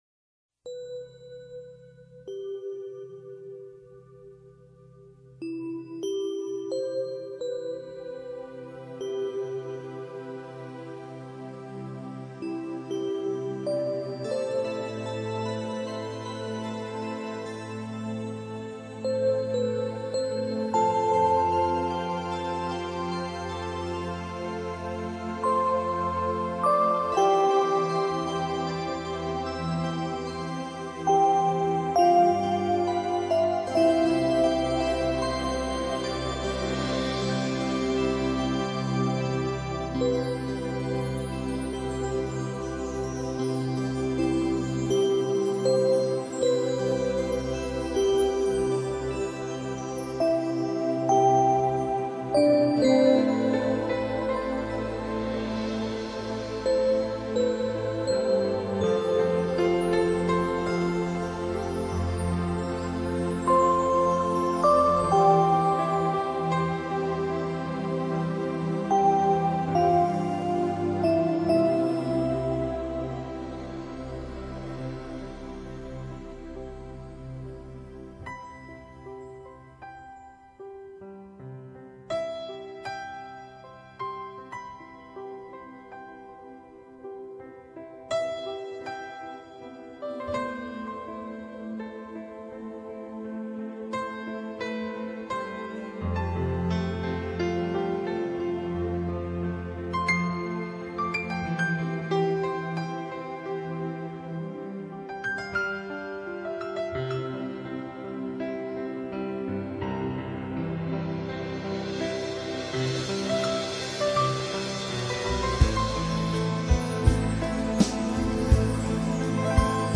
营造出泛着银晖的浪漫情境。